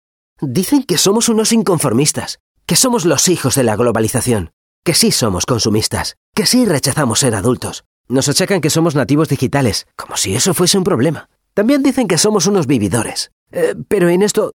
Excelente dicción, vocalización y sonido brillante.
Utilizamos micrófonía Neuman U87 y Audio Technica 4050/CM5.
Sprechprobe: Sonstiges (Muttersprache):